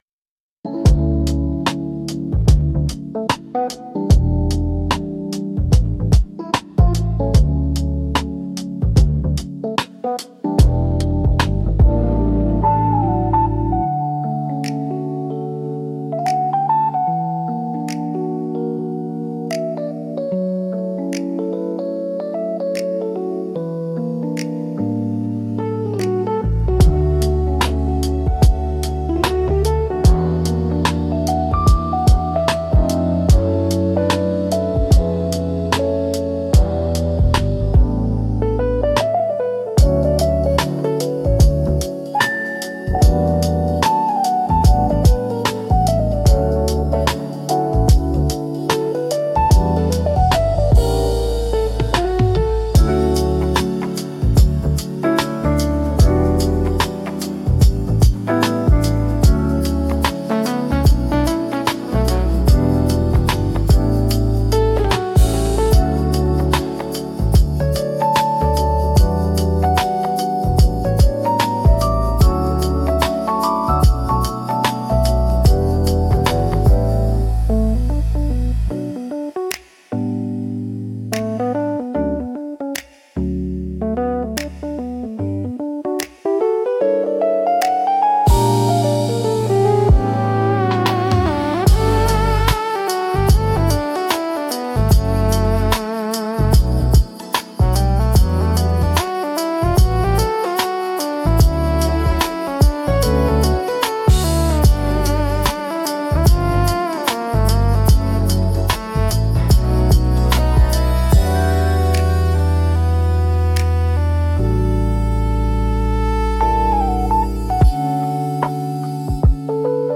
チルアウトは、ゆったりとしたテンポと滑らかで広がりのあるサウンドが特徴のジャンルです。
リラックス効果の高いメロディと穏やかなリズムにより、心地よい安らぎの空間を作り出します。
穏やかで心地よい雰囲気作りに重宝されるジャンルです。